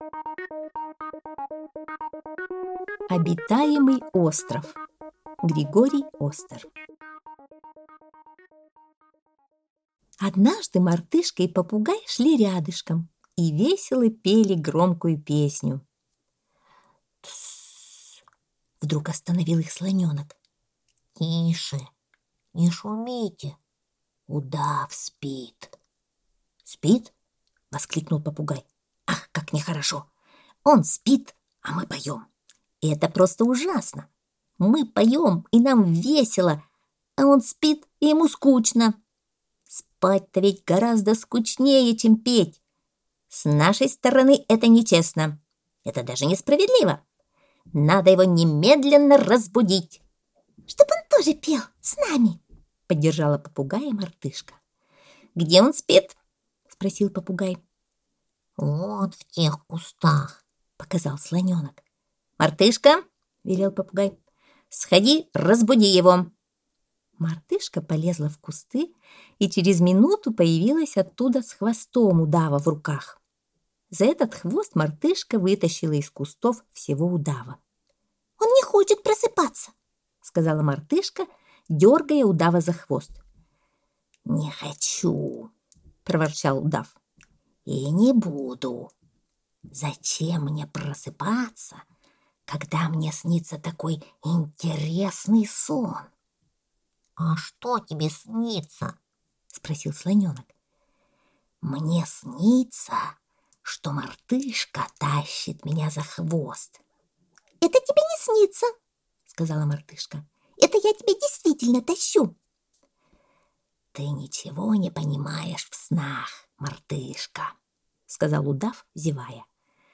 Обитаемый остров - аудиосказка Остера - слушать онлайн